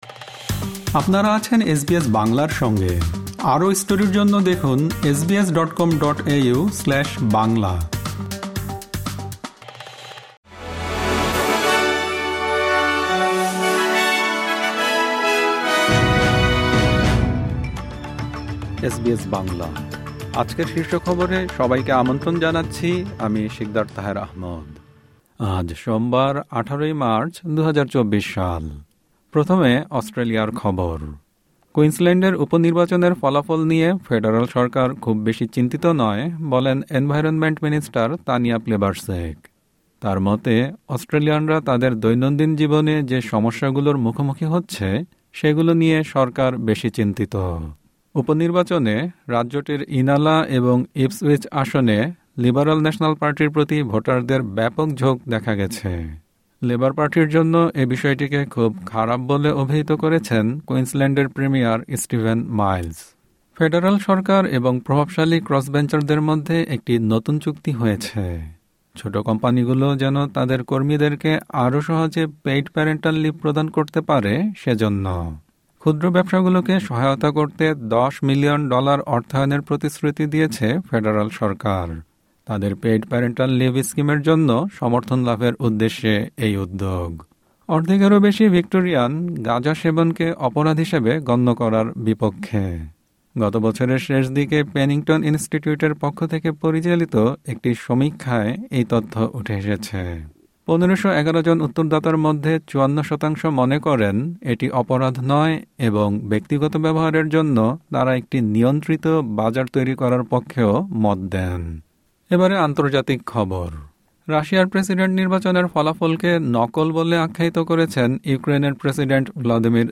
এসবিএস বাংলা শীর্ষ খবর: ১৮ মার্চ, ২০২৪